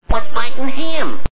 The sound bytes heard on this page have quirks and are low quality.